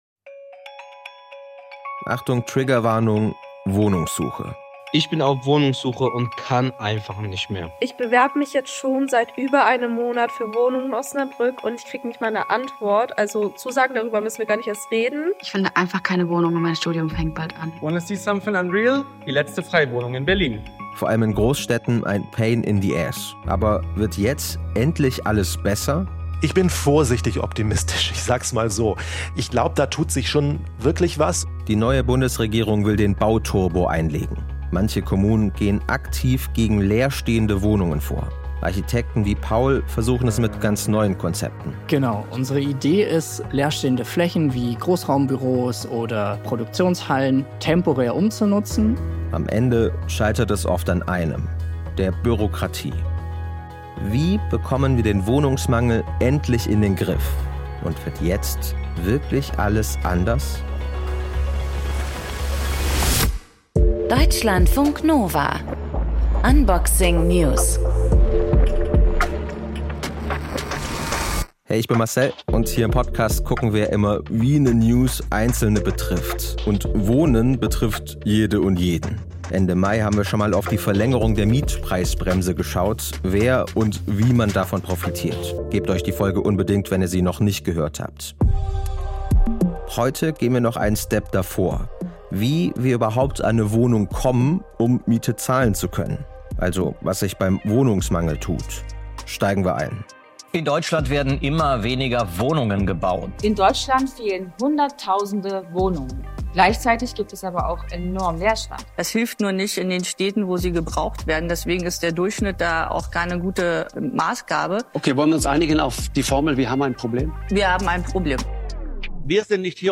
Der Wortwechsel ist das Diskussionsforum bei Deutschlandfunk Kultur – mit interessanten... Mehr anzeigen